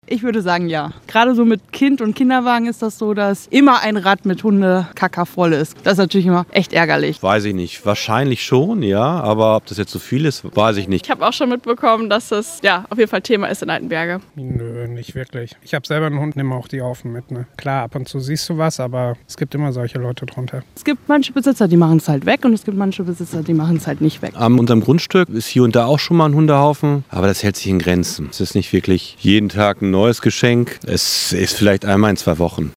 So sehen es die Menschen in Altenberge
umf_hundehaufen_altenberge-v1.mp3